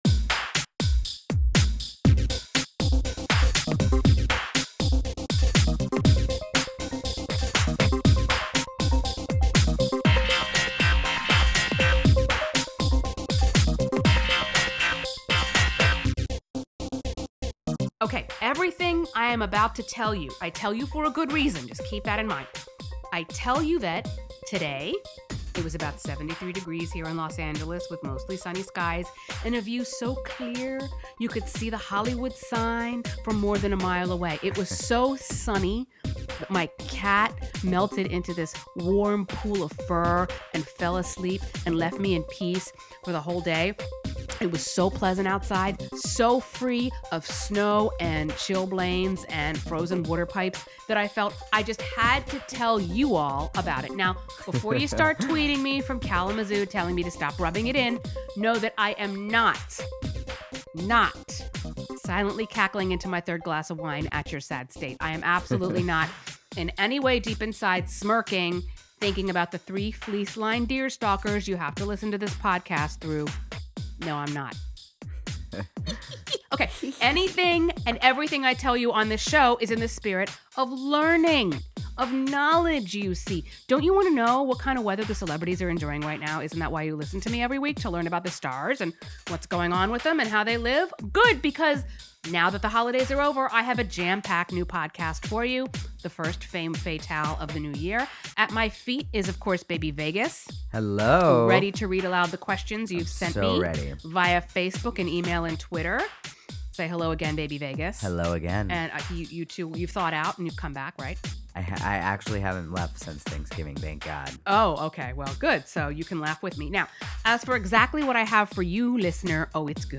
As I recently learned, stars like their assistants to think like an elf all year ‘round, starting right...about...now. I offer you proof of this in my first podcast of the New Year, courtesy of an interview with a woman who served as the assistant for an Oscar-winning actress for more than 25 years.